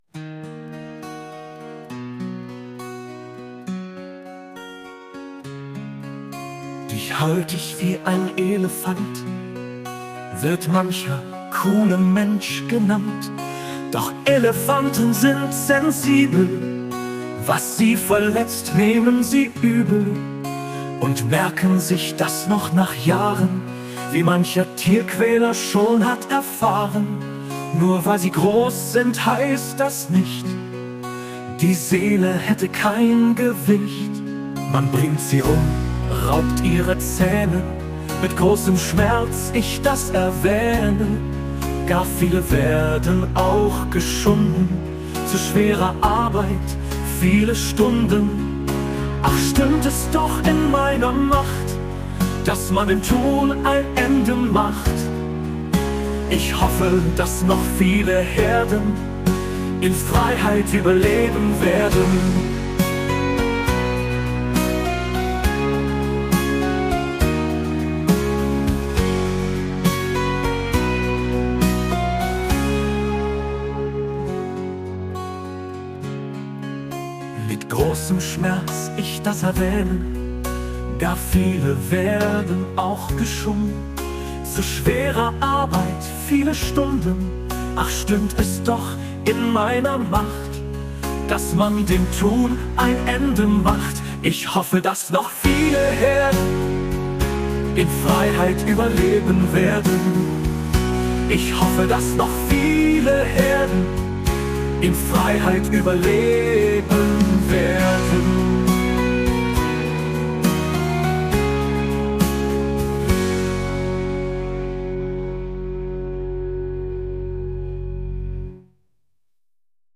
vertont mittels KI von suno
Die Vertonung erfolgte mittels KI von suno und ist deren Nutzung ist nur nichtkommerziell gestattet.